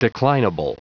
Prononciation du mot declinable en anglais (fichier audio)
Prononciation du mot : declinable